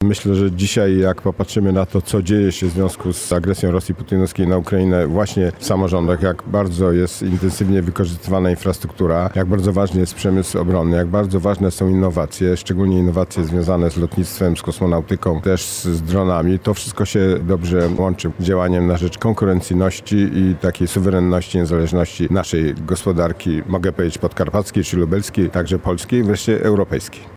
– Regiony są bardzo ważnym i istotnym elementem, jeżeli chodzi o rozwój gospodarczy – mówi przedstawiciel grupy konserwatystów w Komitecie Regionów i marszałek województwa podkarpackiego, Władysław Ortyl.